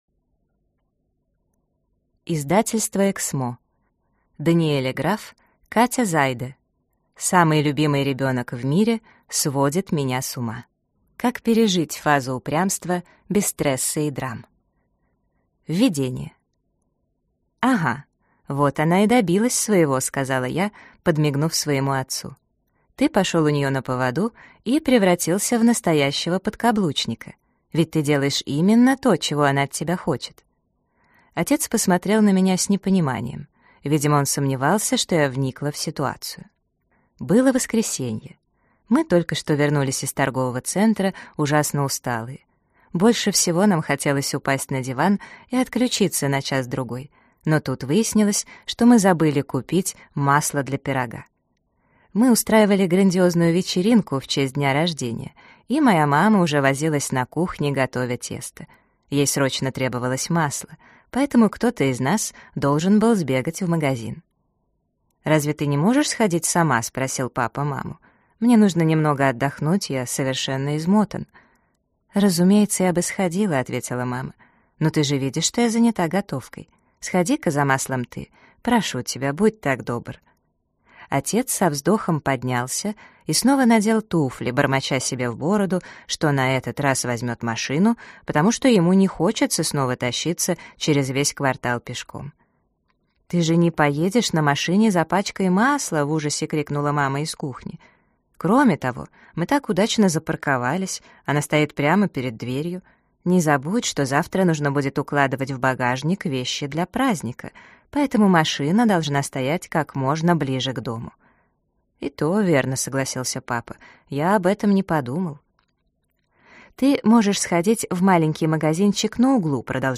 Аудиокнига Самый любимый ребенок в мире сводит меня с ума. Как пережить фазу упрямства без стресса и драм | Библиотека аудиокниг